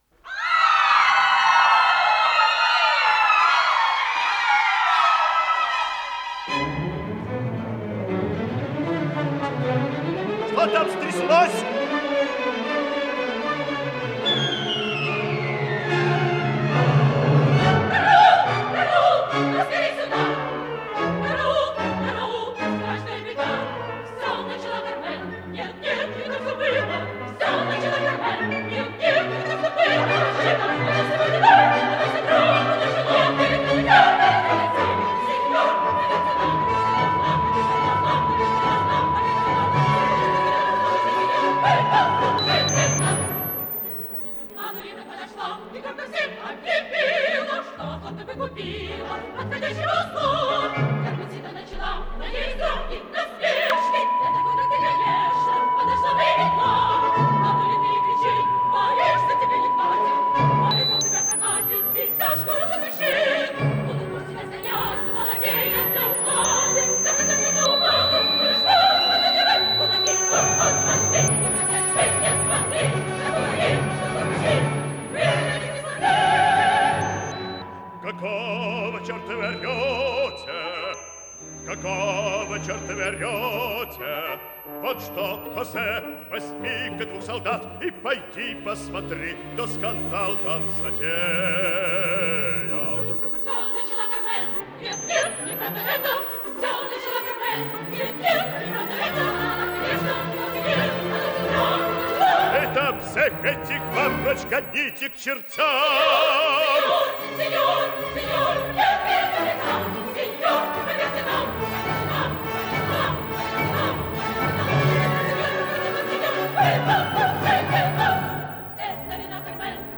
Исполнитель: Солисты, хор и оркестр Московского академического музыкального театра имени Станиславского и Немировича Данченко